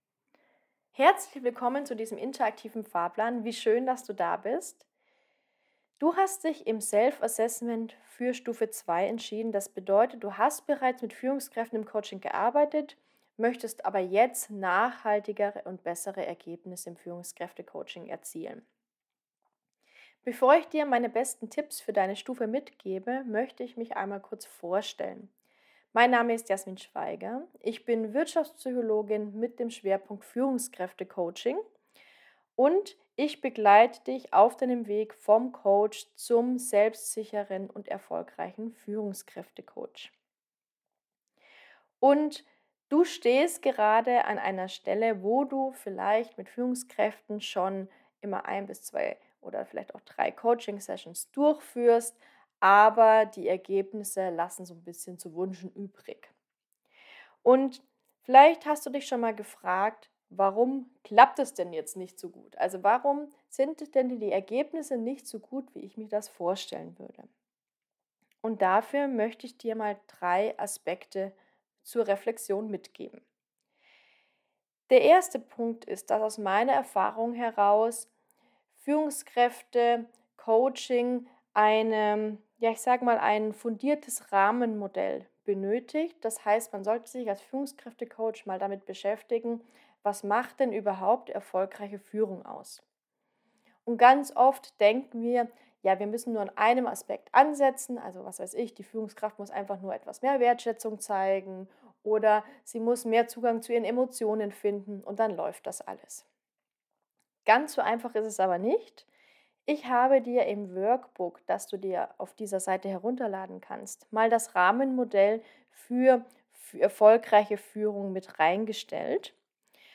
Meine Audionachricht für dich